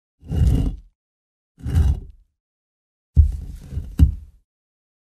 Звуки камней
Могильная плита, камень волокут по камню